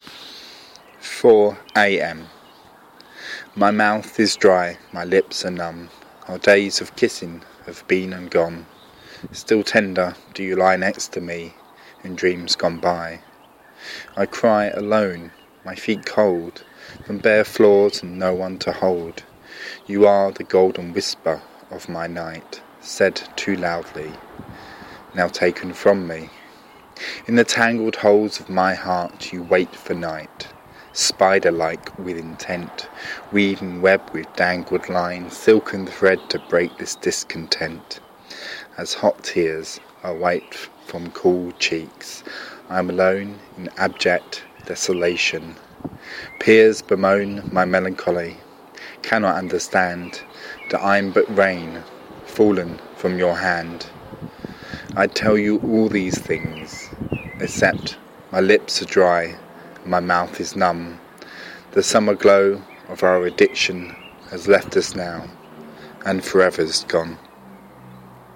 Spoken love poem